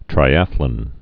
(trī-ăthlən, -lŏn)